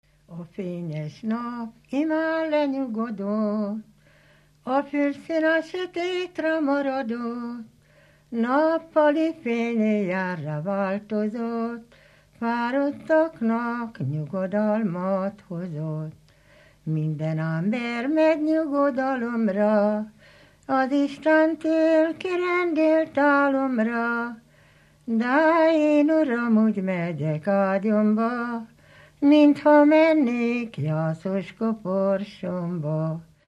Dunántúl - Somogy vm. - Hódoshátihegy (Nikla)
ének
Műfaj: Esti ének
Stílus: 9. Emelkedő nagyambitusú dallamok
Kadencia: 4 (5) 5 1